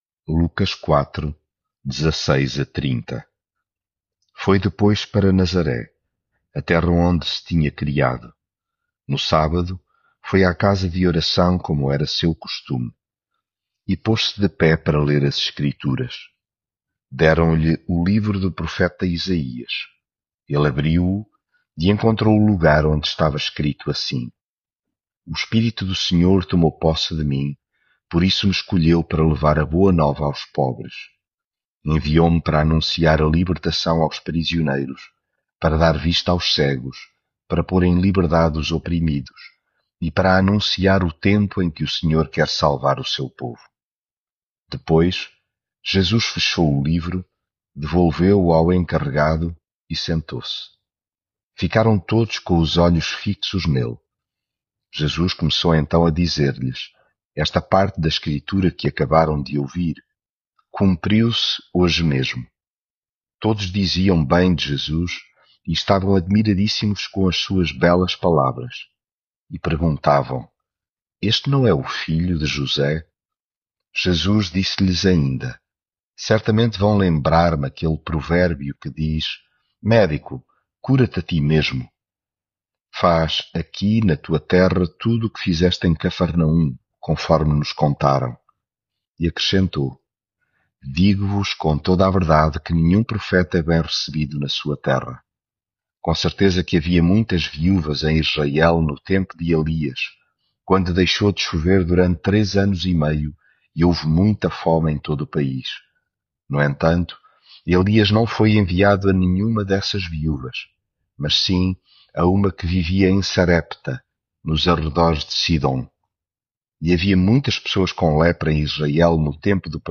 devocional Lucas leitura bíblica Foi em seguida para Nazaré, a terra onde se tinha criado.